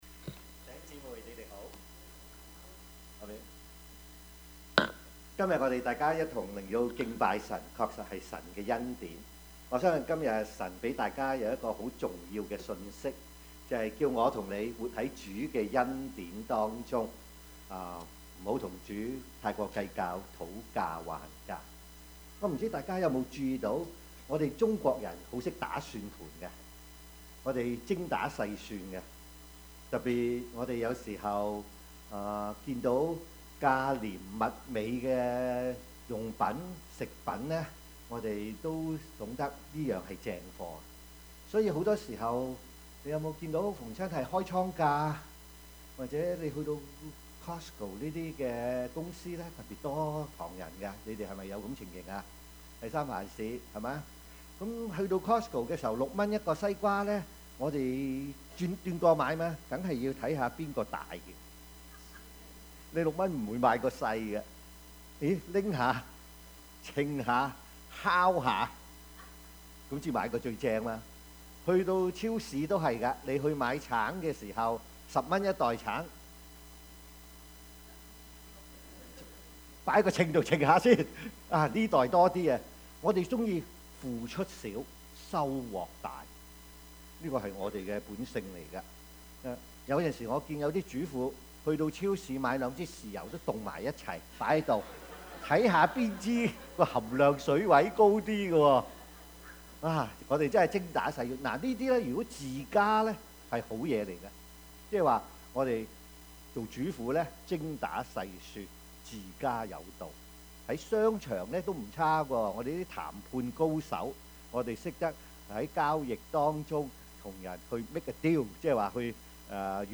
Service Type: 主日崇拜
） Topics: 主日證道 « 識時務者為俊傑 比喻的信息: 活在主的恩典中 »